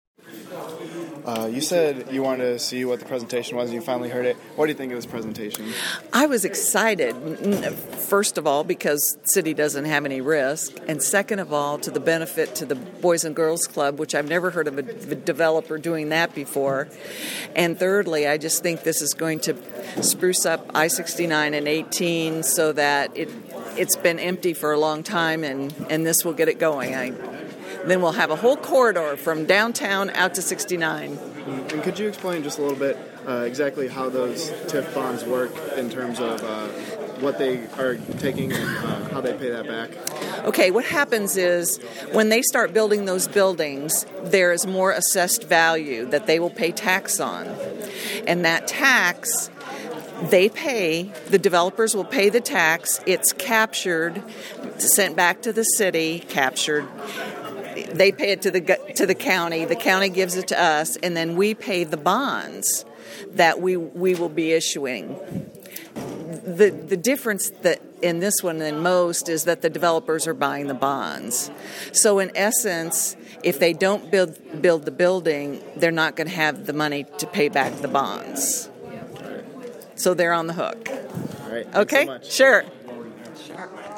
Councilwoman Madonna French discusses the developments coming to Marion.